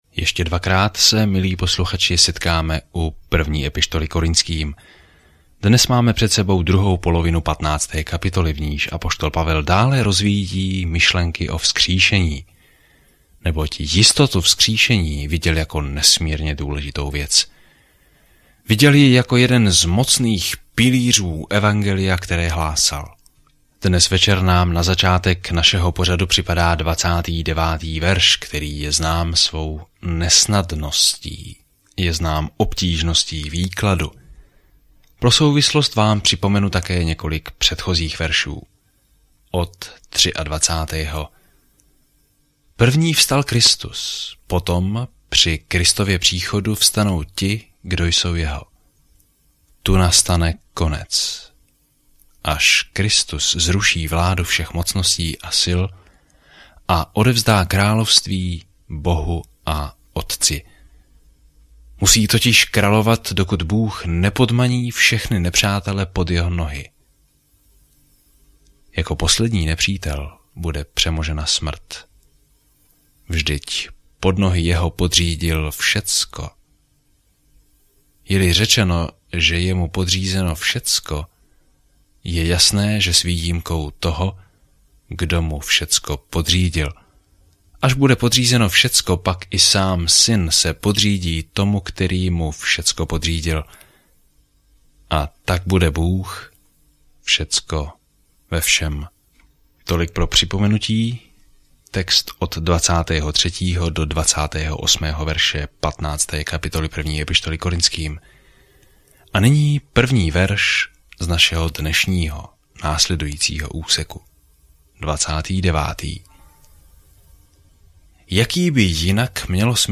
Písmo 1 Korintským 15:29-58 Den 25 Začít tento plán Den 27 O tomto plánu "Jak by měl žít křesťan?" Je tématem, kterým se zabývá první dopis Korinťanům, praktickou péči a nápravu problémům, kterým mladí křesťané čelí? Denně procházejte 1. listem Korinťanům, zatímco budete poslouchat audiostudii a číst vybrané verše z Božího slova.